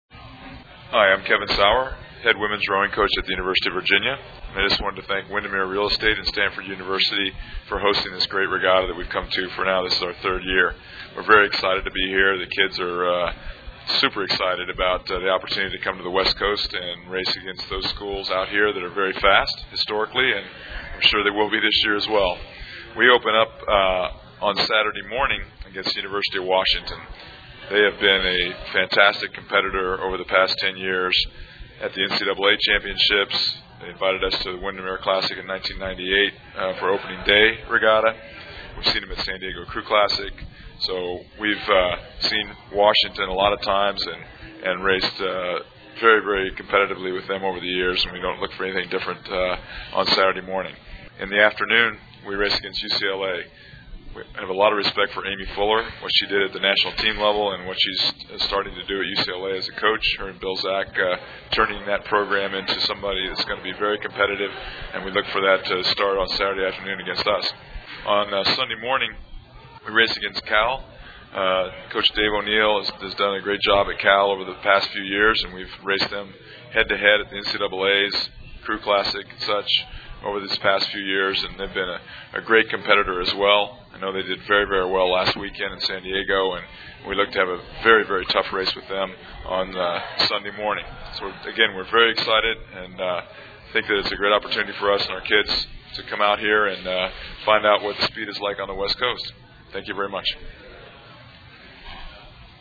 Team Head Coach Pre-race Comments